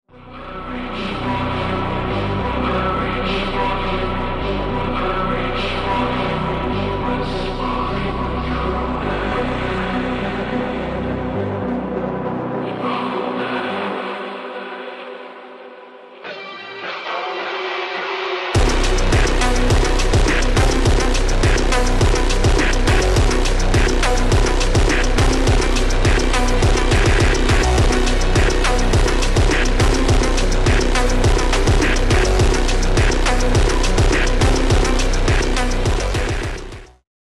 (Slowed)